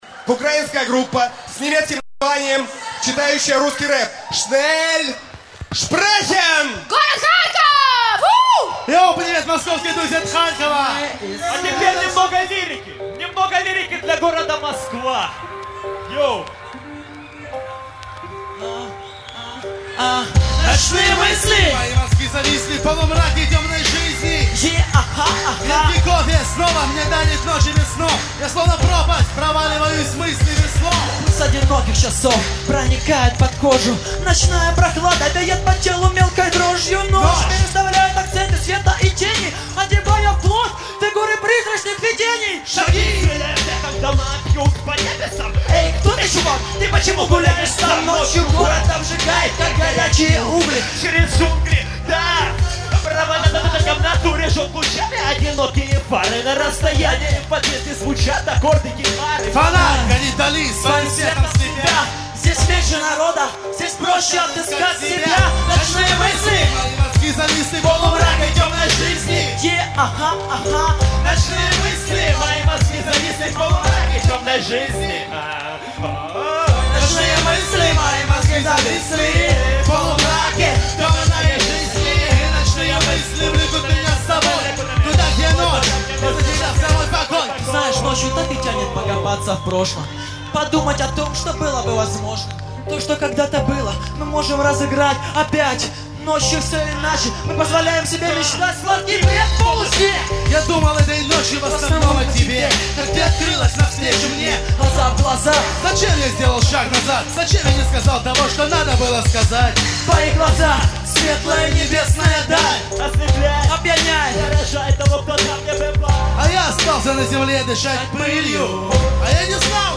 • Жанр: Хип-хоп